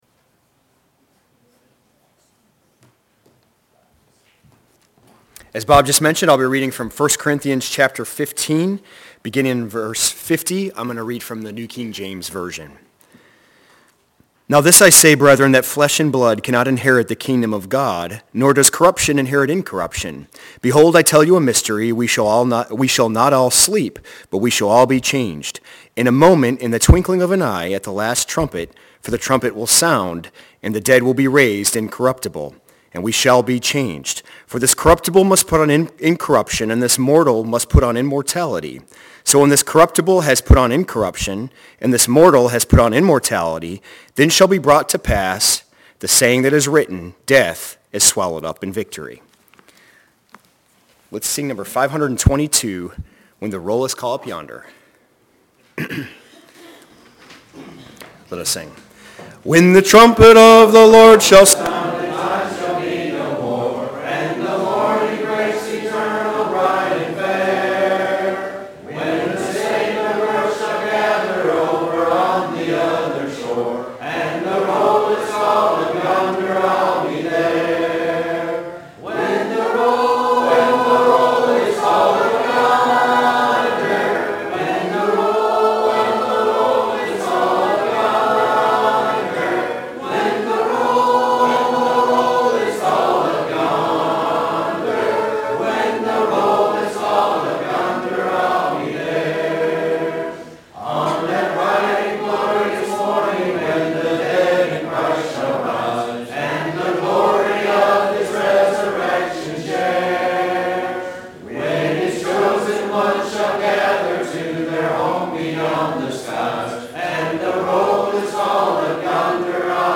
Sunday PM – 5th Sunday Night Sing – 03.30.25